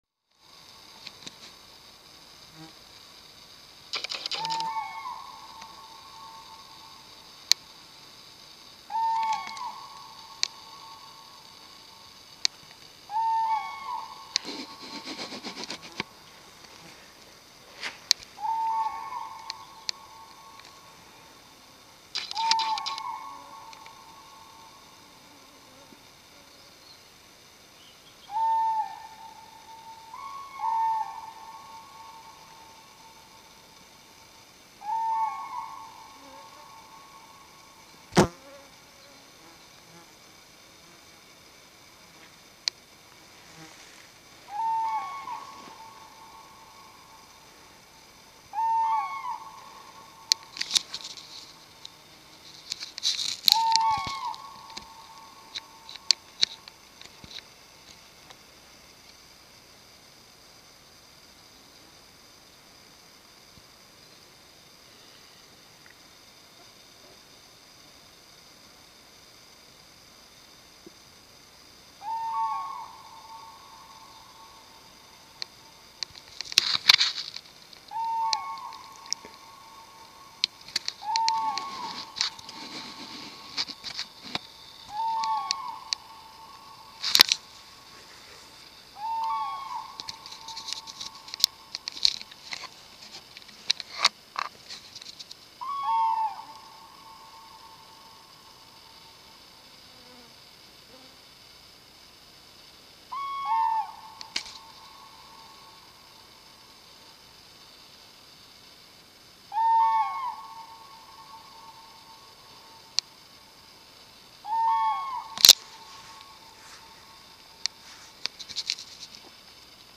Вы можете слушать онлайн или скачать знаменитое курлыканье, крики и звуки общения этих грациозных птиц в формате mp3.
Крики журавлей на дальнем болоте